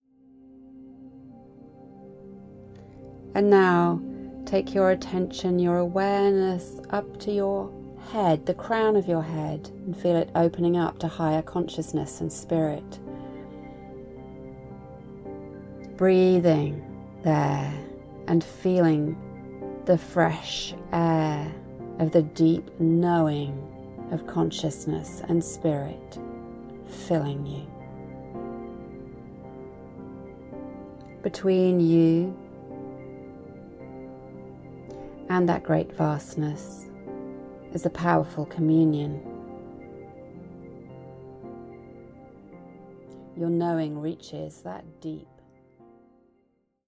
The album contains 19 tracks and 4 hours of recordings including guided meditations, instructional material and “re-sets”.